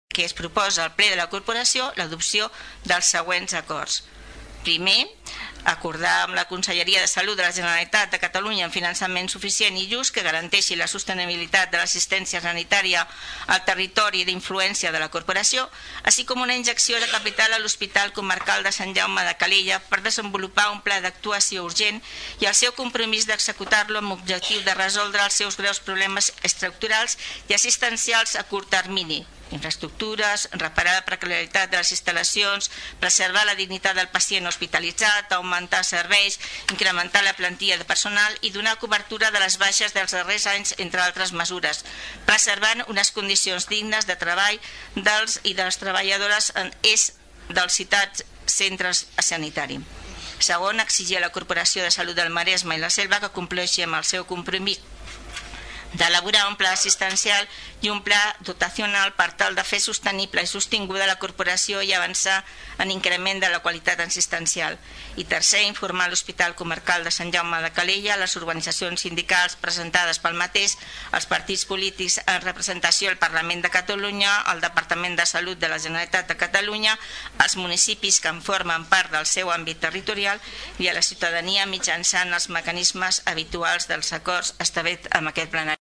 El grup municipal del PSC va presentar en el darrer ple una moció a favor d’exigir al Servei Català de la Salut, millorar les inversions en infraestructures i recursos humans a l’Hospital Comarcal de Sant Jaume de Calella per garantir la qualitat dels serveis.
La portaveu de la moció, Carme Fernández, explicava els punts de la qüestió.